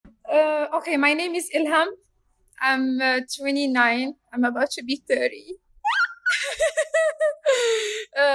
• Moroccan Female
• Age 30s
Morroco-Africa-Female-30-s.mp3